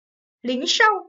língshòu - lính sâu Bán lẻ